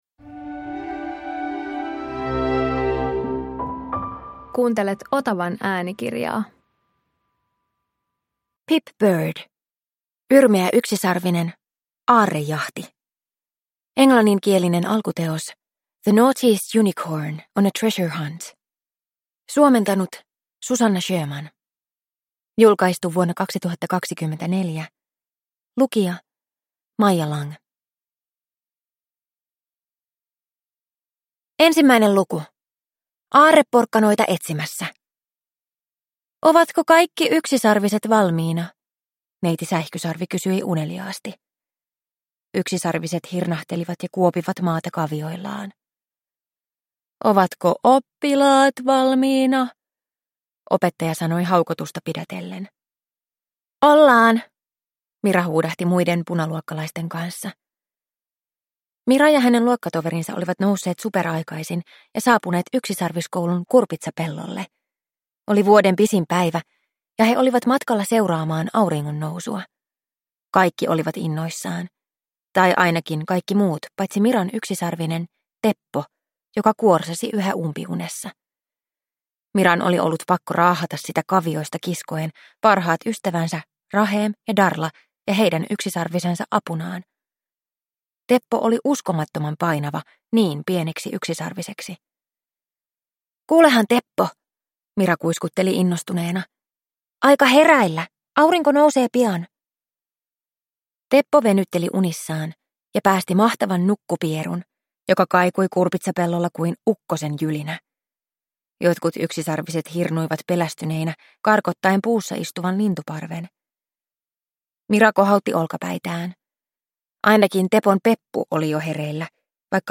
Yrmeä yksisarvinen - Aarrejahti – Ljudbok